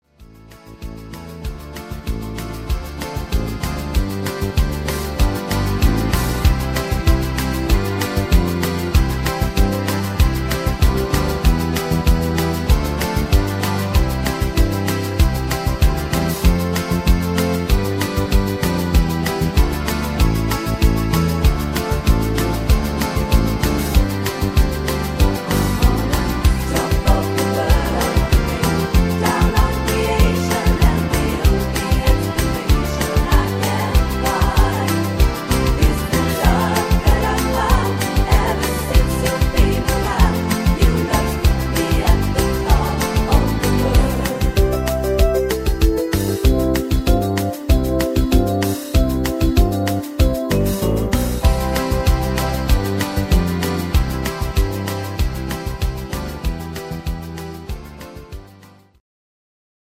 Buy Playback abmischen Buy